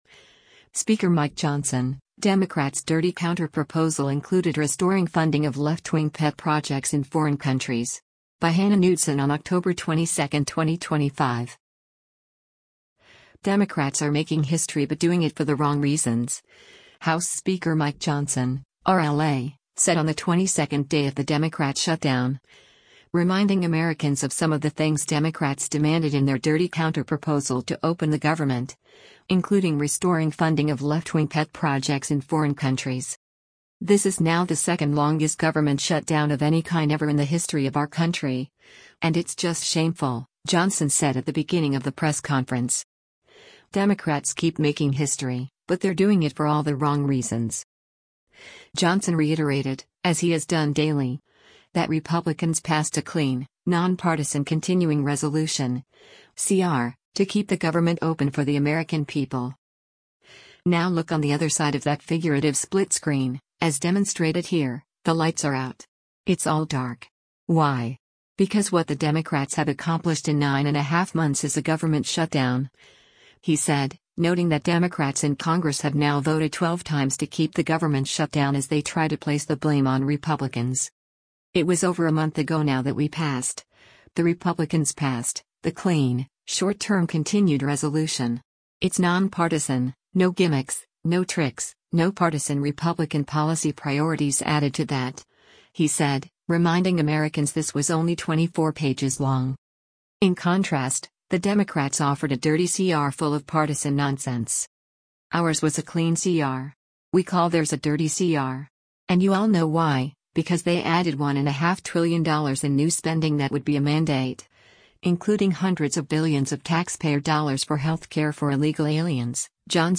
US House Speaker Mike Johnson, a Republican from Louisiana, during a news conference at th